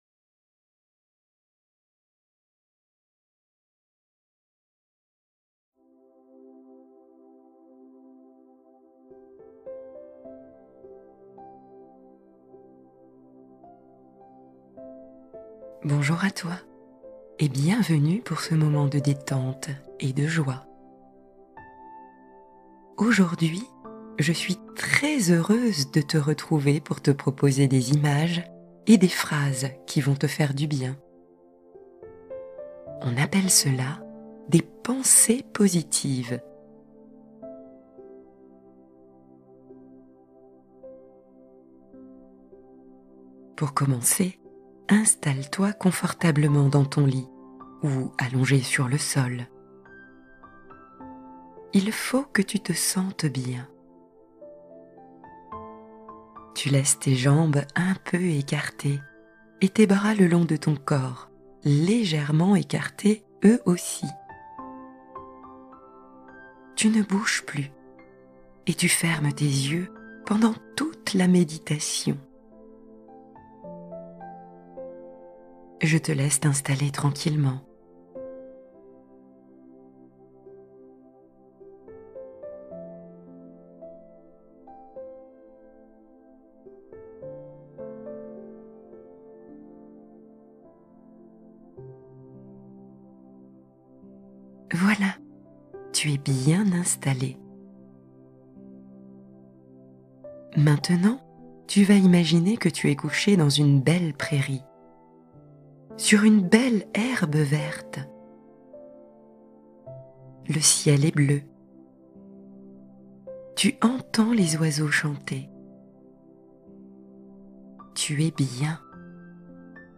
Relaxation de Noël : rêveries guidées pour petits et grands